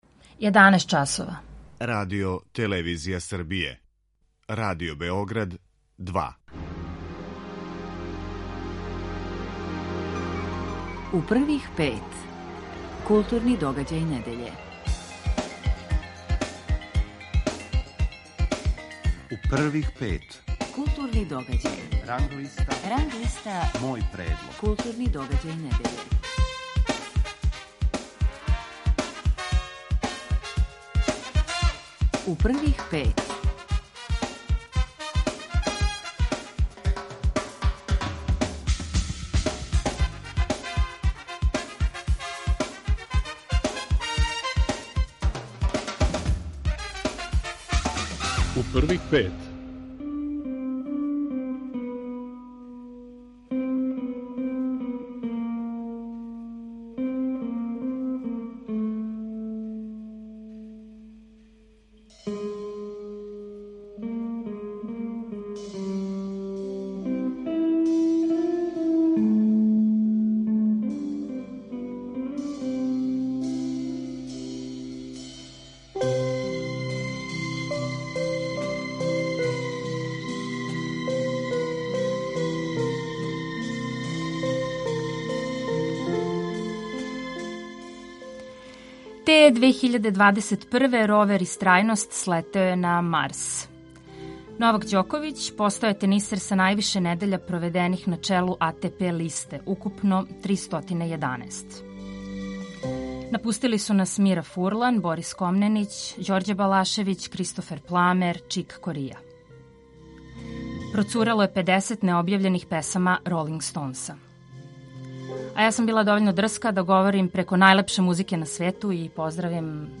Гост емисије је композитор Зоран Симјановић.